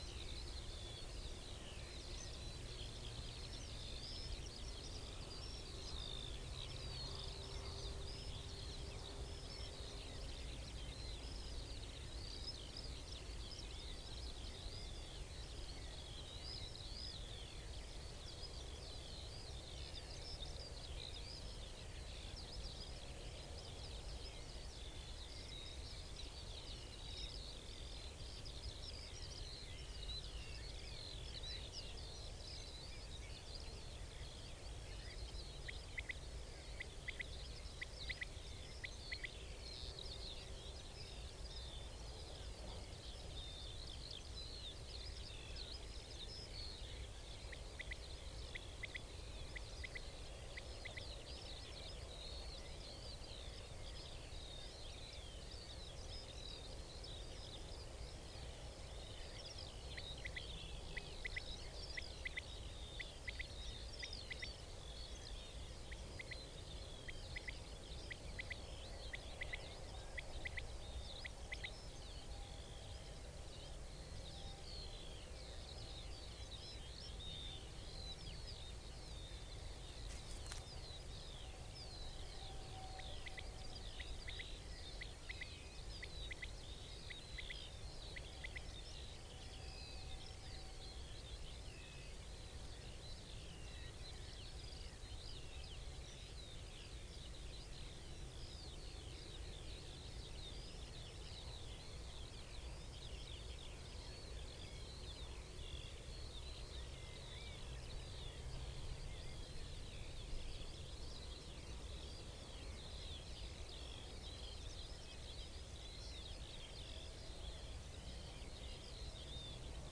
Columba palumbus
Coturnix coturnix
Corvus corone
Turdus merula
Sylvia communis
Alauda arvensis